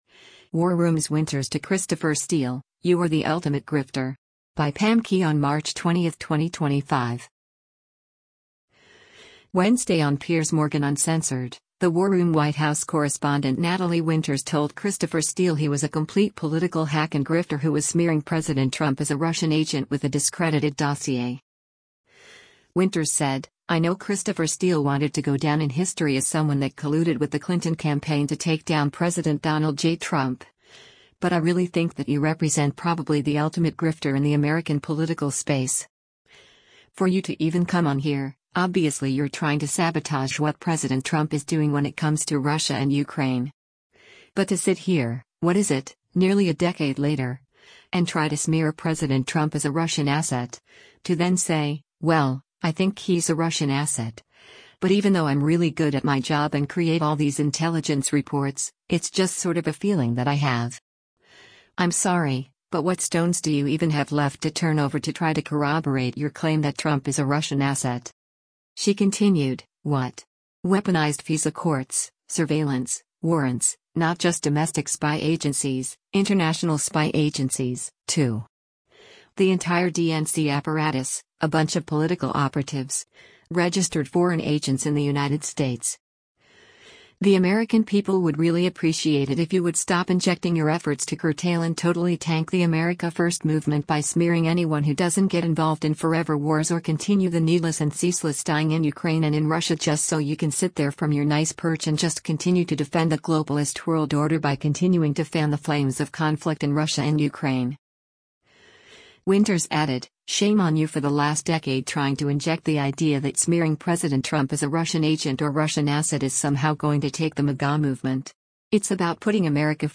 Wednesday on “Piers Morgan Uncensored,” the “War Room” White House correspondent Natalie Winters told Christopher Steele he was a “complete political hack and grifter” who was “smearing President Trump as a Russian agent” with the discredited dossier.